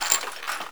horror
Skeleton Bones Rattle 5